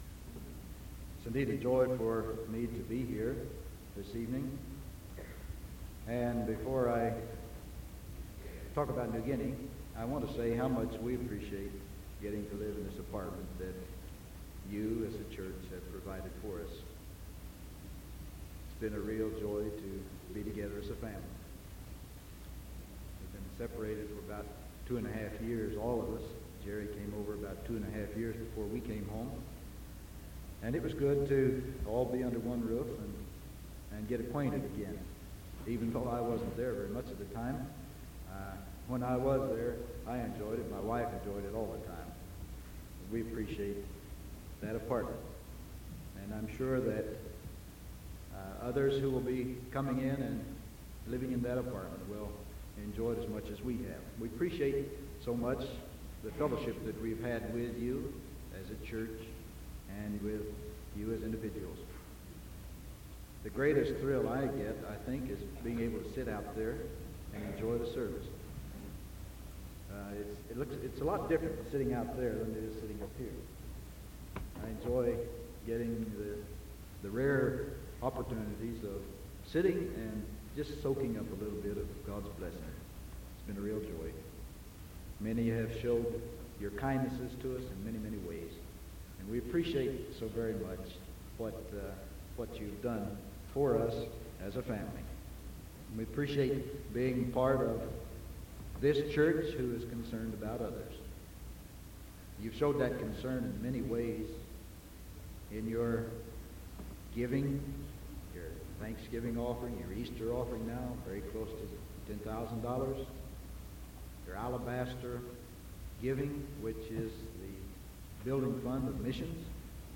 Sermon May 12th 1974 PM